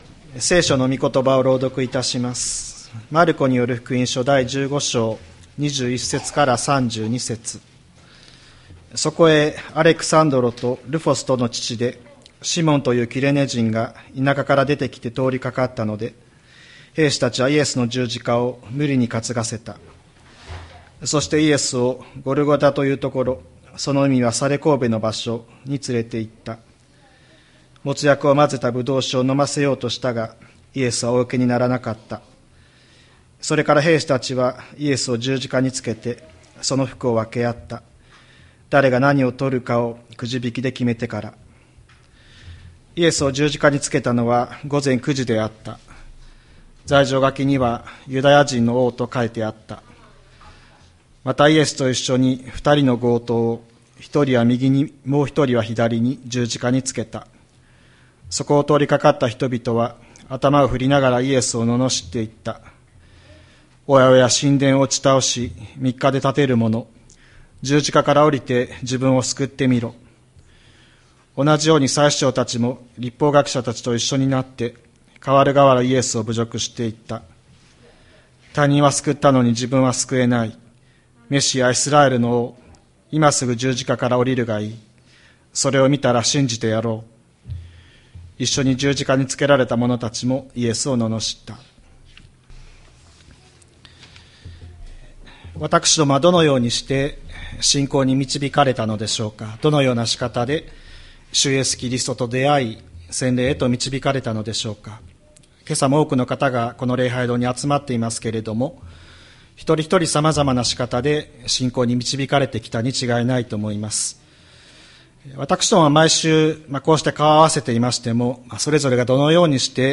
千里山教会 2025年04月06日の礼拝メッセージ。